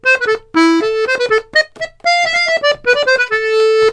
S120POLKA4-R.wav